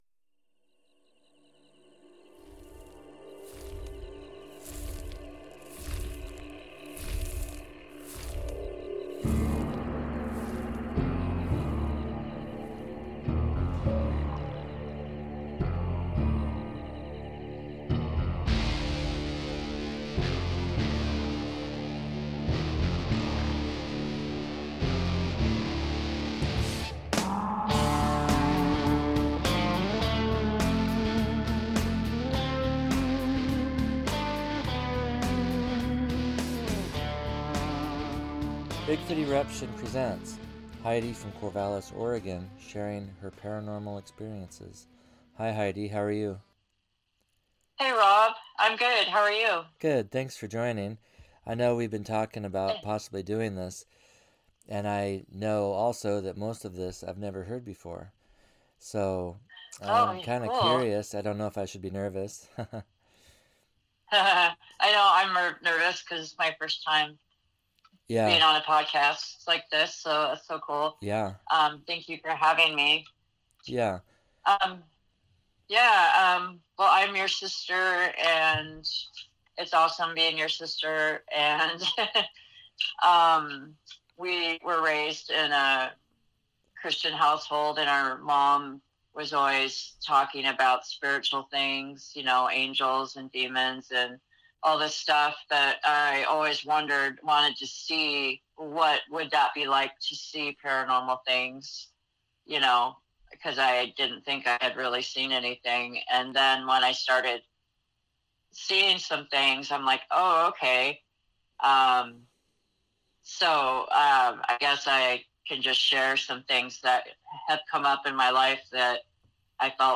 In most cases an entity is communicating with her. We ask, are these things paranormal or supernatural and what is the difference? Note: We have agreed to re-record this episode as we now have better equipment; however, with new equipment comes new problems.